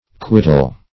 Quittal \Quit"tal\ (kw[i^]t"tal), n.
quittal.mp3